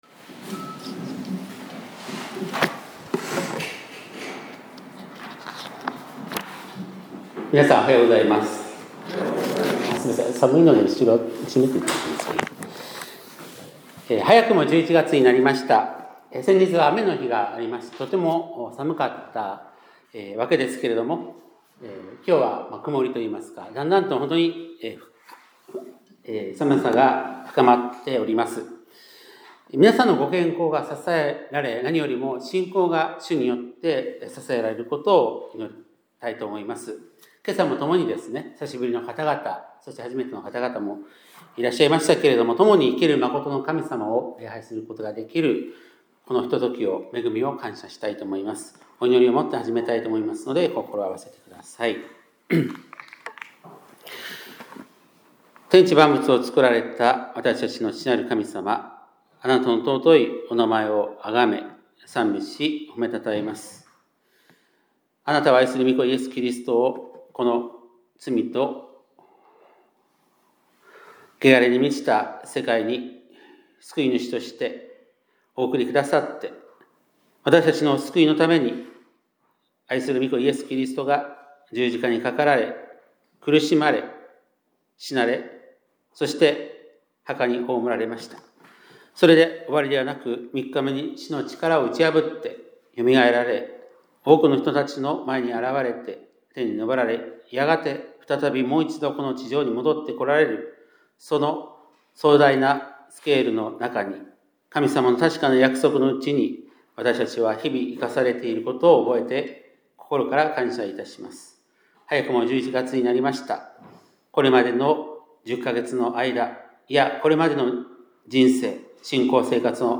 2025年11月2日（日）礼拝メッセージ - 香川県高松市のキリスト教会
2025年11月2日（日）礼拝メッセージ